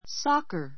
soccer 小 A1 sɑ́kər サ カ ｜ sɔ́kə ソ カ 名詞 サッカー football a soccer ball a soccer ball サッカーボール a soccer player a soccer player サッカー選手 a soccer match [team] a soccer match [team] サッカーの試合[チーム] play soccer play soccer サッカーをする